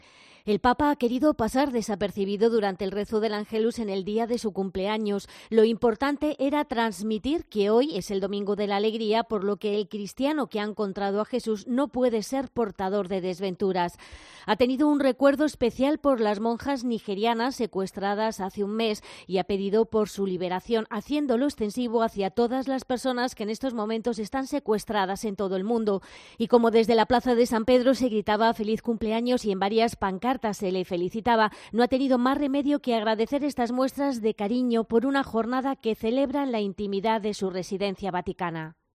"Me uno de corazón al llamamiento de los obispos de Nigeria para la liberación de seis Hermanas del Corazón Eucarístico de Cristo, secuestradas hace alrededor de un mes en su convento de Iguoriakhi", solicitó el pontífice tras el rezo del Ángelus.
Desde la ventana del Palacio Apostólico, Francisco aseguró que reza "con insistencia por todas las demás personas que se encuentran en esta dolorosa condición", para que "con motivo de la Navidad, puedan finalmente regresar a sus casas".